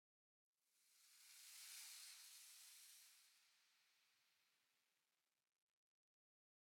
1.21.5 / assets / minecraft / sounds / block / sand / sand11.ogg
sand11.ogg